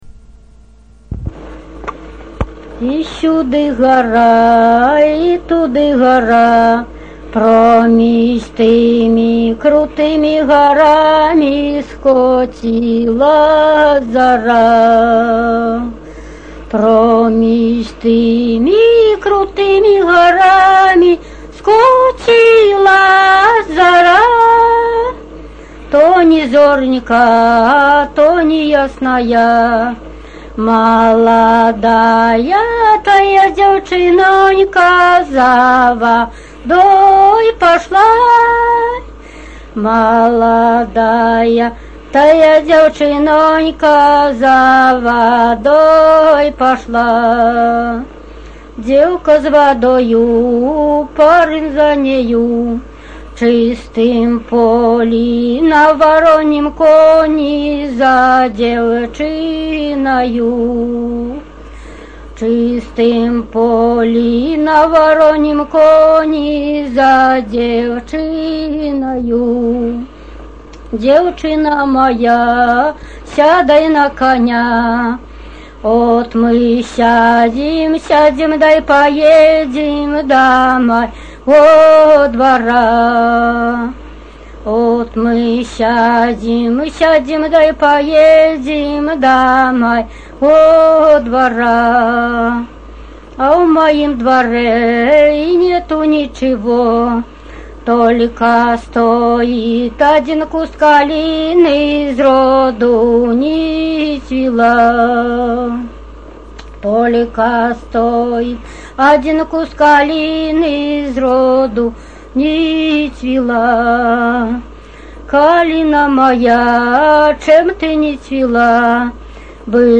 Тема: ЭБ БГУ::Беларускі фальклор::Паэзія сямейна-абрадавага комплексу::вясельныя песні